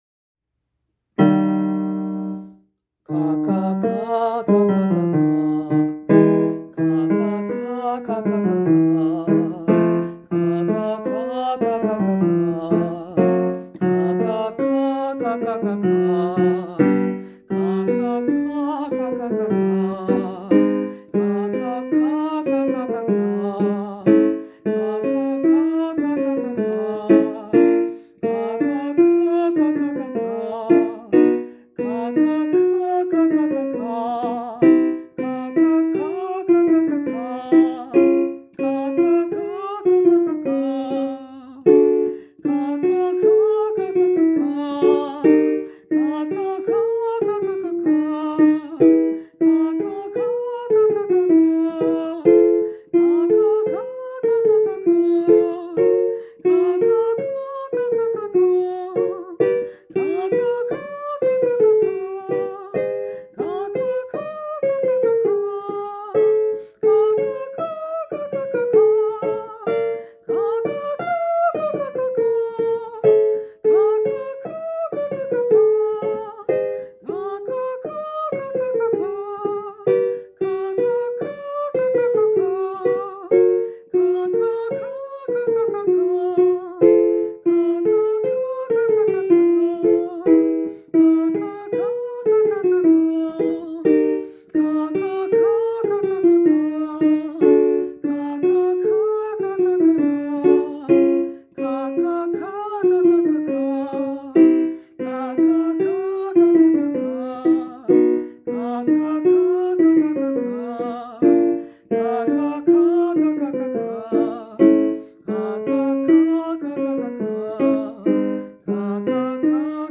Special vocal warm-ups developed to quickly see results